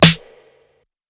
Stripclub Snare.wav